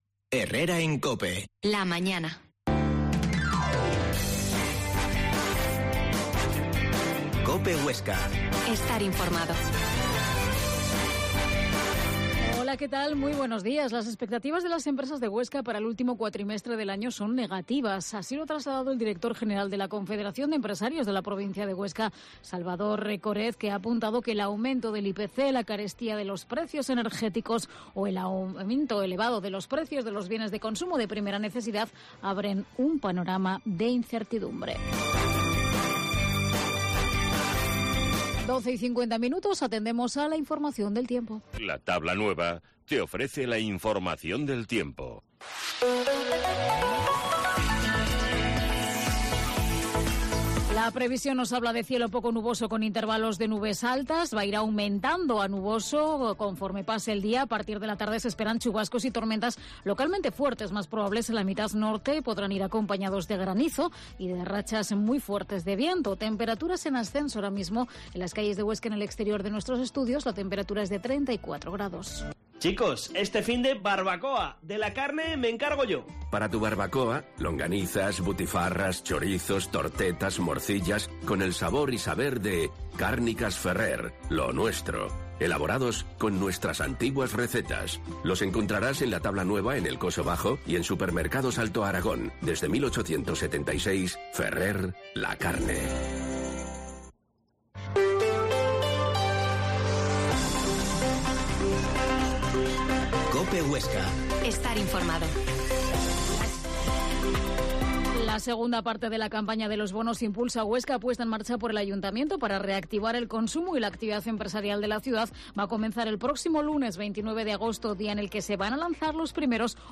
Herrera en COPE Huesca 12.50h Entrevista a Manuel Larrosa, alcalde de Fiscal